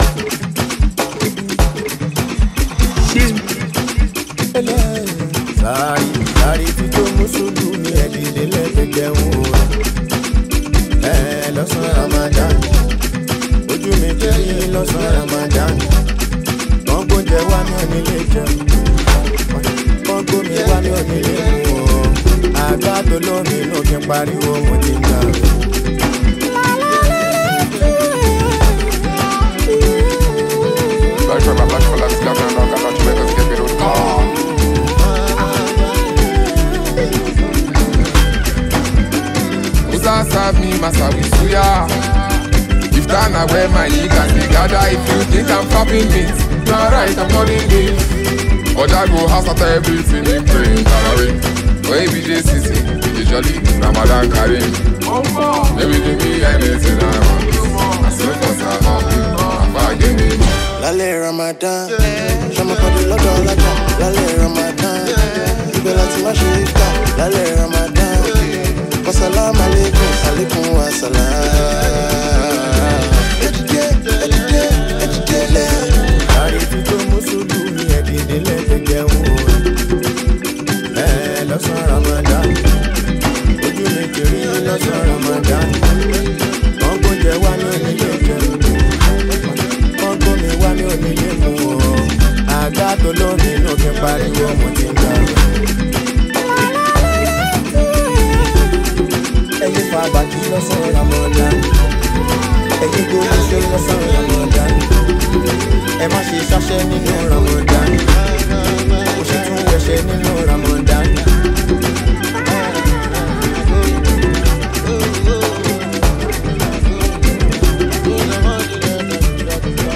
Talented afrobeat singer and songwriter